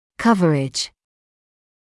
[‘kʌvərɪʤ][‘кавэридж]покрытие; зона действия